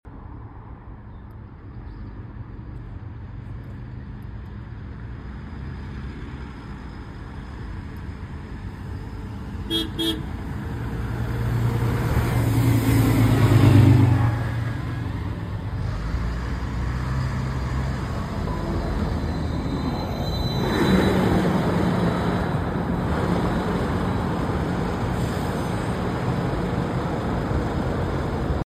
*Sound driver in 1st bus* sound effects free download
Dublin bus Volvo B9TL ADL enviro500, Wrightbus electroliner, VT36, EW77 are seen @ UCD Bus park on routes 39a and X32